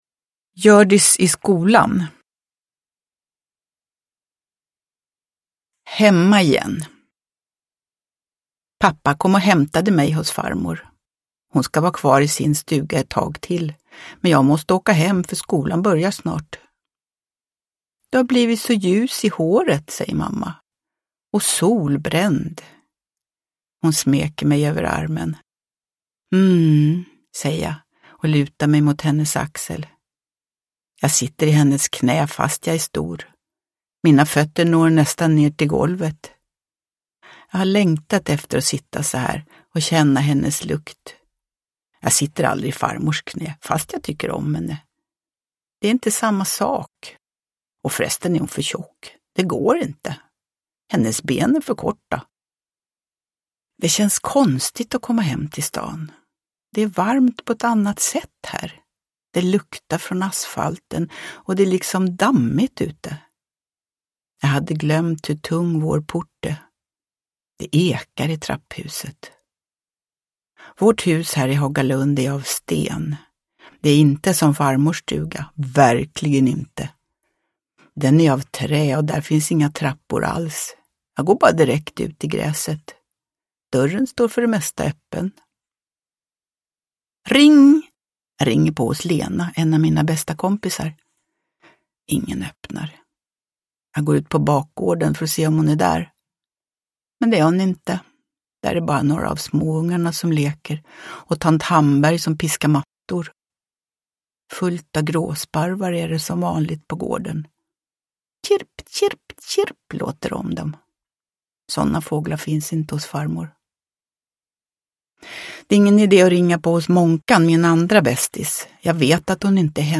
Hjördis i skolan – Ljudbok – Laddas ner
Uppläsare: Jujja Wieslander